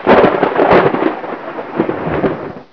Thunder1
THUNDER1.WAV